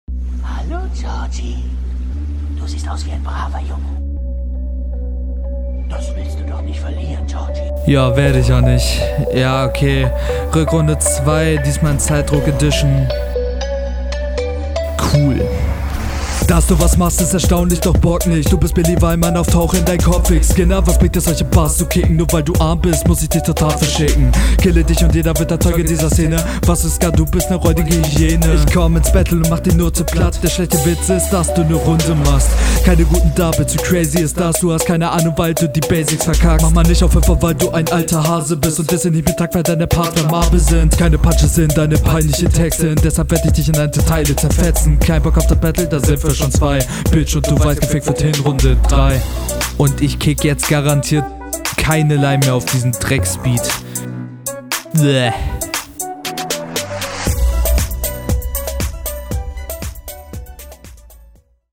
Auf dem Beat flowst du nach meinem Geschmack viel besser.